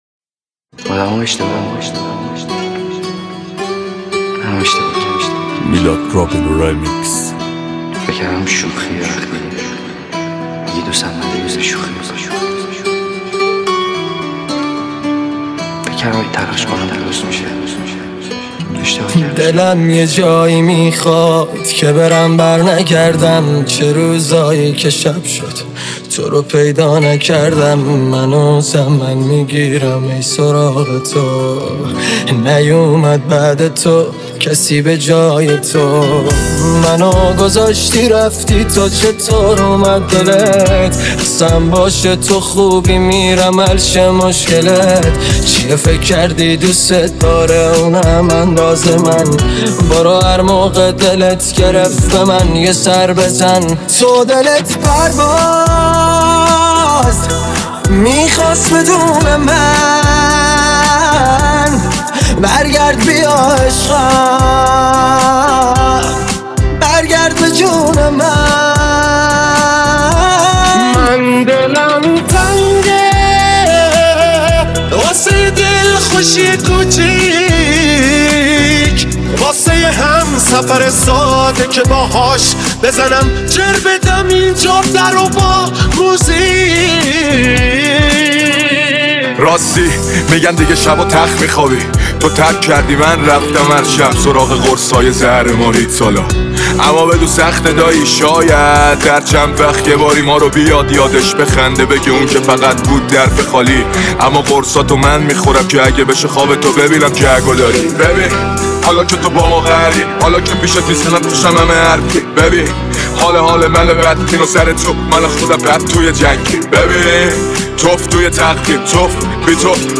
ریمیکس رپ خفن